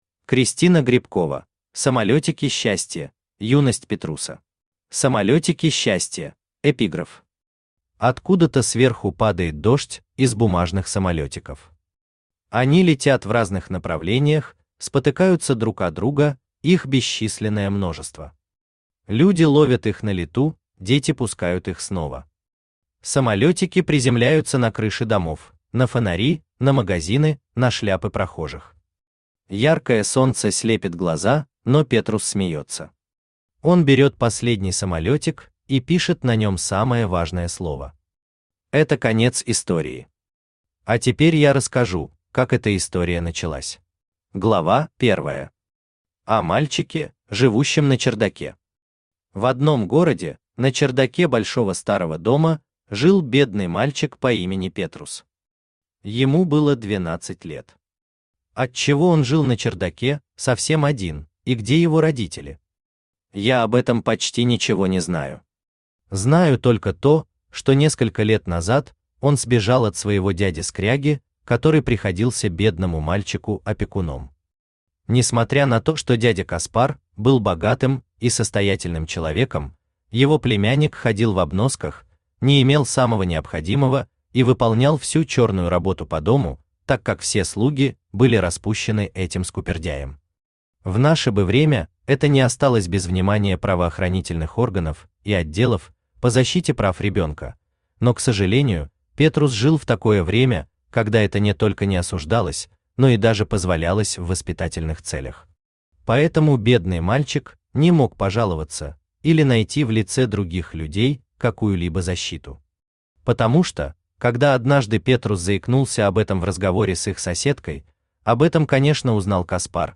Аудиокнига Самолетики счастья. Юность Петруса | Библиотека аудиокниг
Юность Петруса Автор Кристина Грибкова Читает аудиокнигу Авточтец ЛитРес.